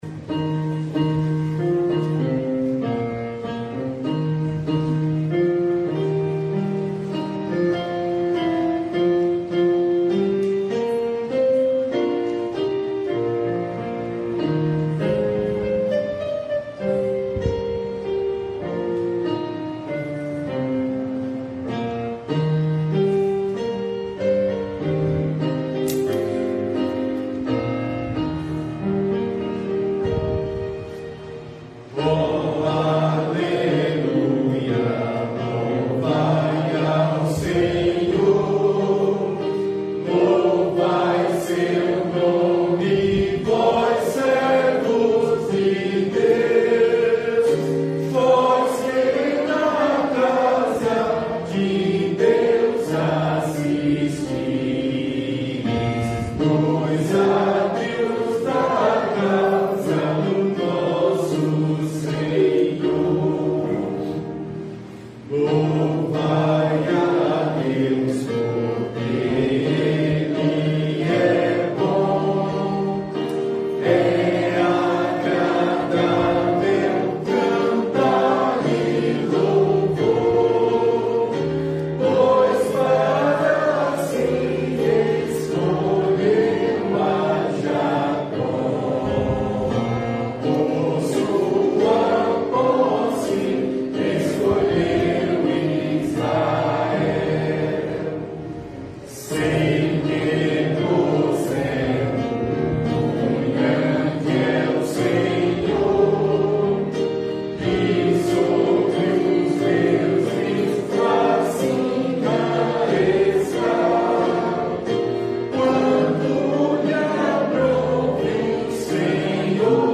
Melodia tradicional irlandesa
salmo_135B_cantado.mp3